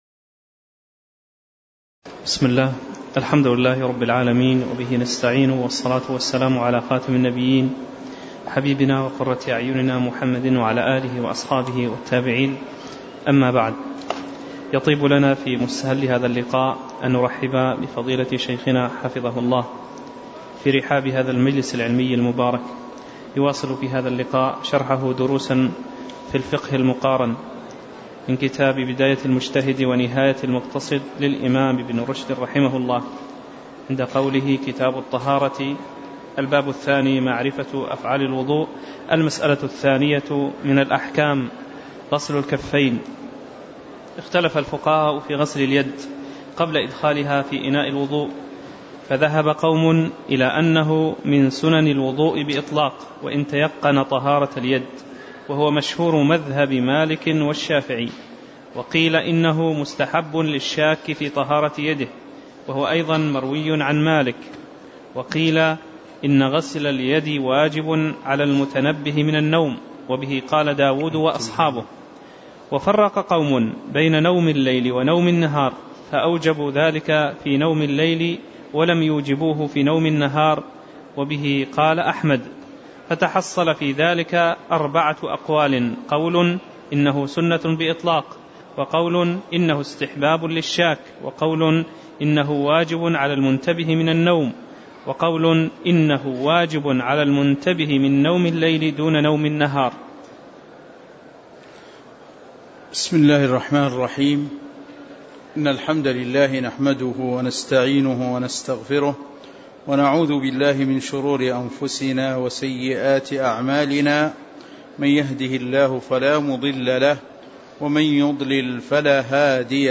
تاريخ النشر ٣٠ جمادى الأولى ١٤٣٩ هـ المكان: المسجد النبوي الشيخ